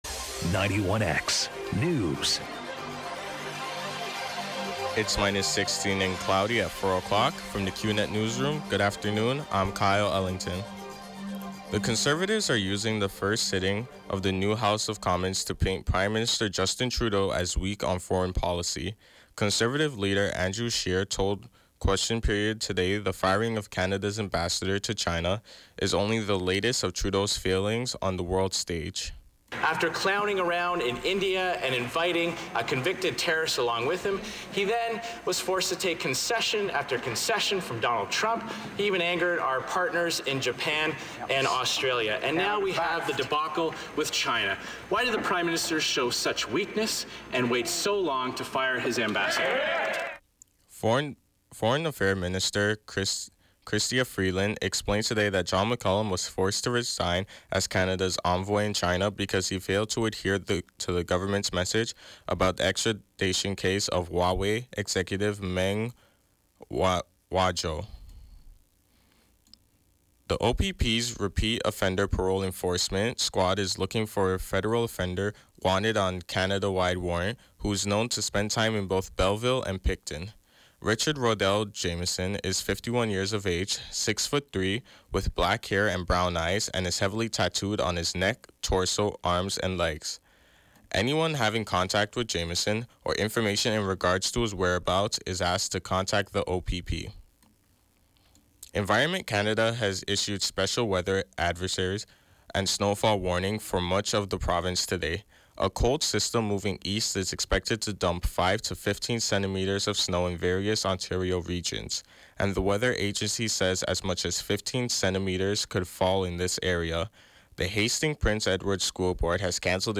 91X Newscast: Monday January 28, 2019, 4 p.m.